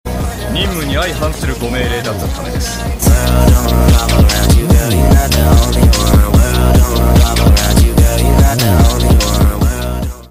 his jp voice is so deep im 🫄